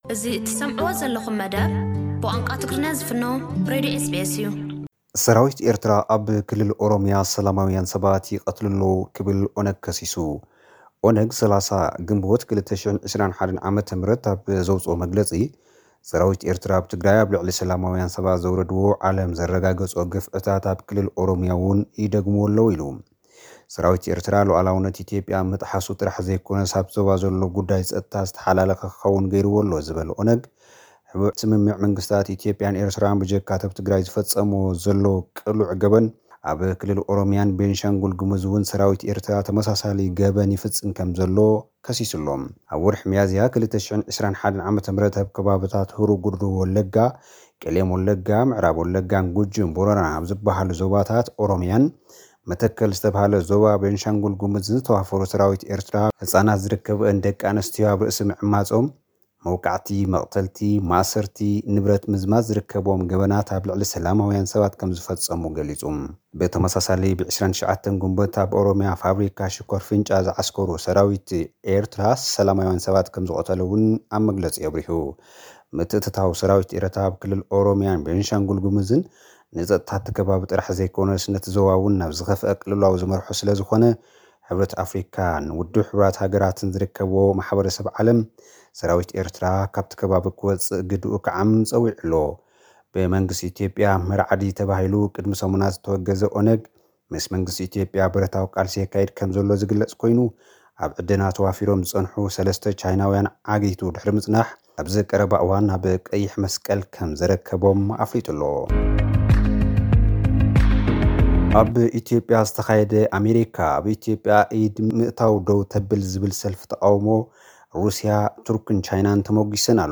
ኦነግ ሰራዊት ኤርትራ ኣብ ኦሮምያ ኣብ ልዕሊ ሰላማውያን ሰባት ገበን ይፍጽሙ ኣለዉ ክብል ከሲሱ። (ሓጸርቲ ጸብጻባት)